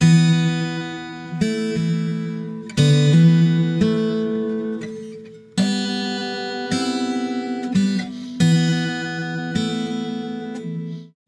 85 Bpm 波萨吉他
描述："Wren bossa" 和弦: Amaj7/D9/G6/C7/Bm7 D7/。
Tag: 85 bpm Jazz Loops Guitar Acoustic Loops 1.90 MB wav Key : A